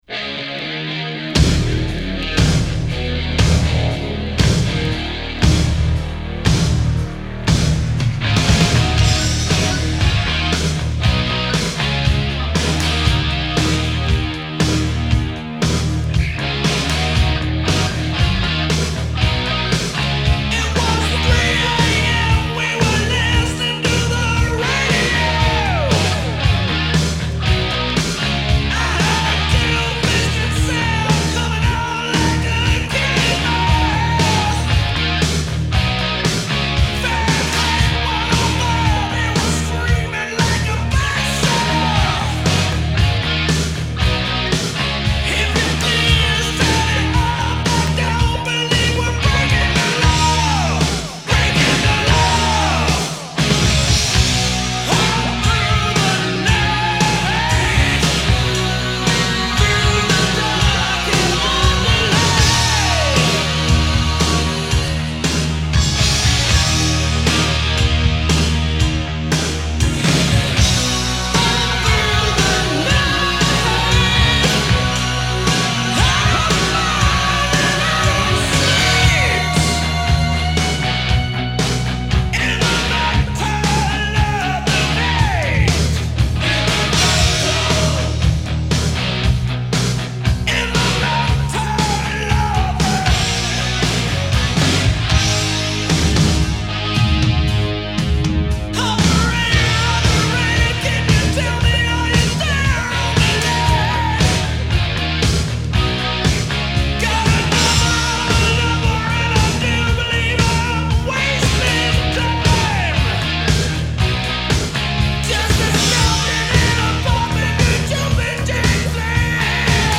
Canadian metal band